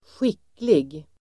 Uttal: [²sj'ik:lig]